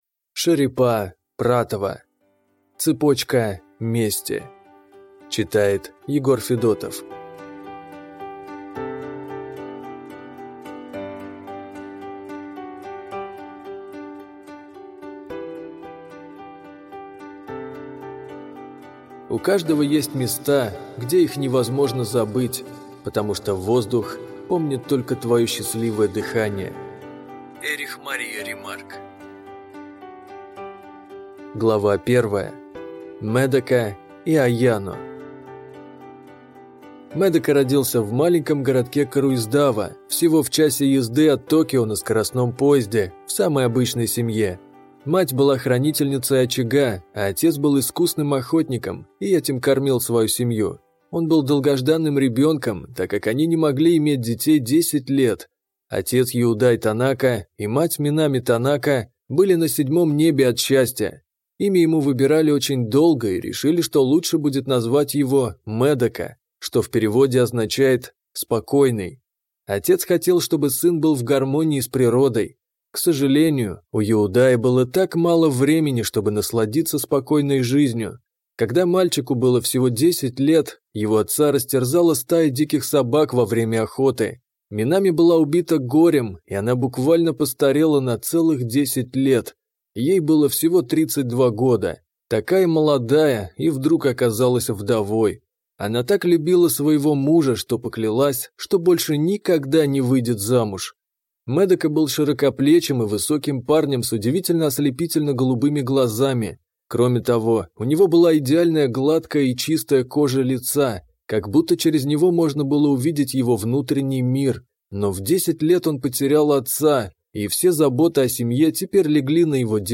Аудиокнига Цепочка мести | Библиотека аудиокниг